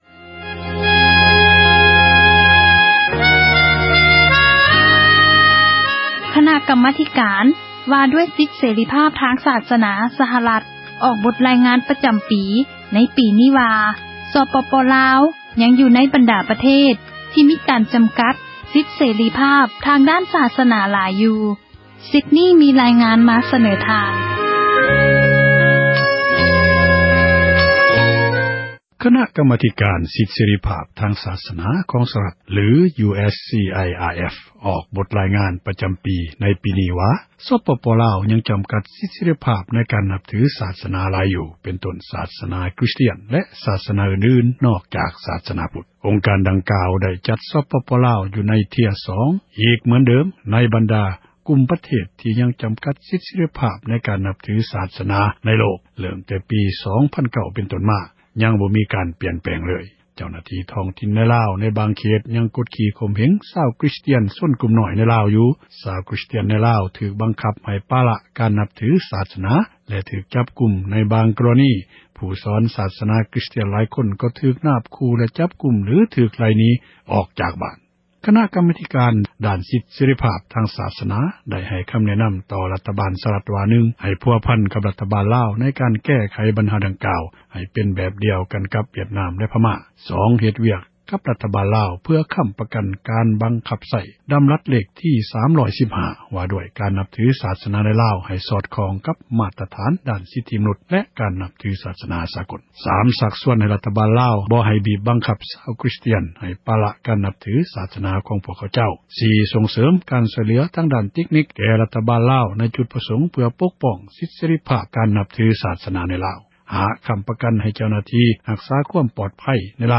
ດັ່ງເຈົ້າຫນ້າທີ່ ສິດເສຣີພາບ ທາງດ້ານສາສນາ ສະຫະຣັດ ທ່ານນຶ່ງເວົ້າວ່າ: ສຽງ...